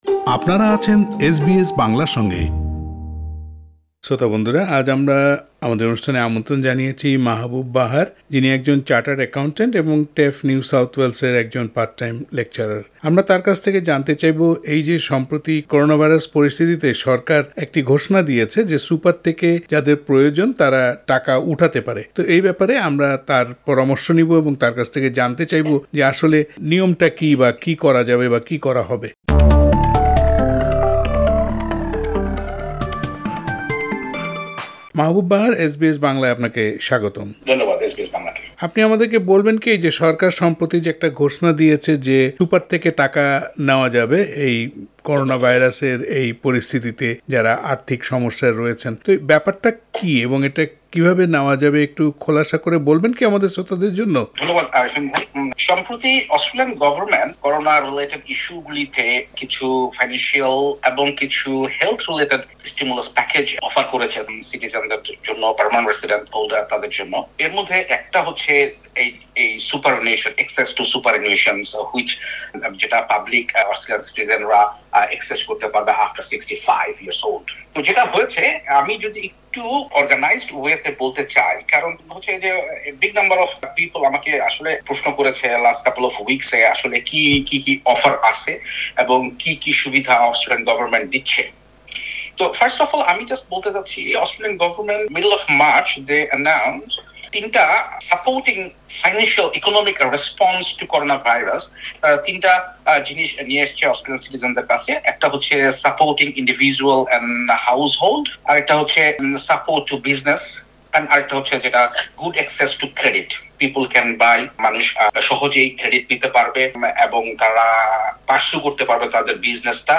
কিন্তু আন্তর্জাতিক শিক্ষার্থীরা সরকারি কোনো সহায়তা পাচ্ছেন না। তবে যারা ১ বছরের বেশি সময় এ দেশে আছেন তারা সুপার ফান্ড থেকে অর্থ তুলতে পারবেন। সুপার ফান্ড থেকে আর কারা কিভাবে ফান্ড উঠাতে পারবেন এই সব কিছু নিয়ে এস বি এস বাংলার সঙ্গে কথা বলেছেন